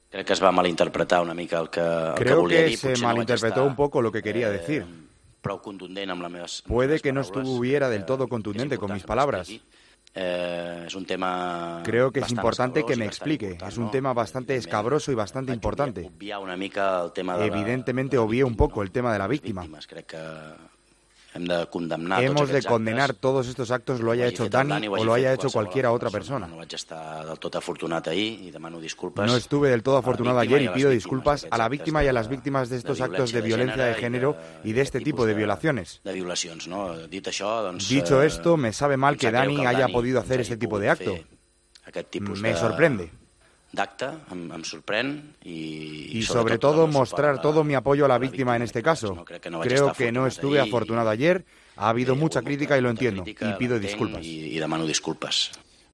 "Creo que se malinterpretó un poco lo que quería decir. Quizá no fui demasiado claro ayer. Obvié un poco el tema de la víctima y tenemos que condenar todos estos actos. Quiero expresar mi apoyo a la víctima y a todas las víctimas de este tipo de violencia", dijo nada más empezar la rueda de prensa posterior al partido, antes de que comenzara el turno de preguntas de los periodistas.